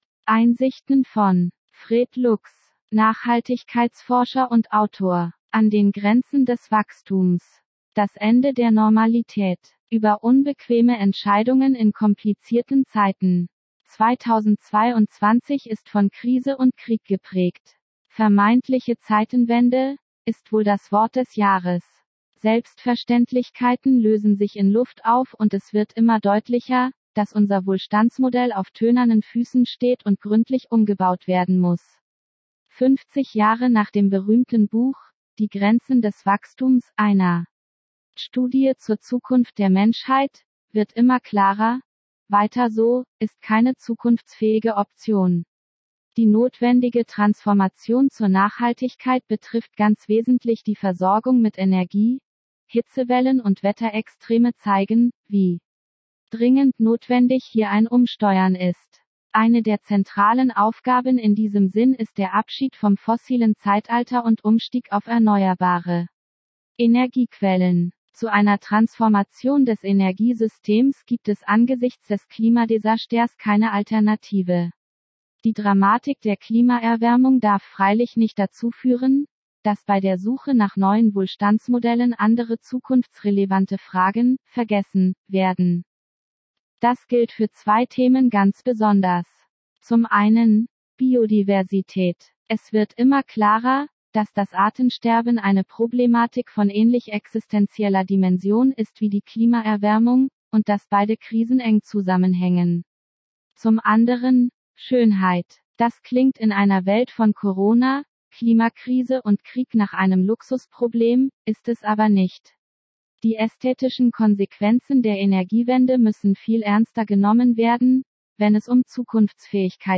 Einsichten Deutsch Weiblich Schwedisch Weiblich English Weiblich Italian Weiblich 2 views Share Download How was the audio ?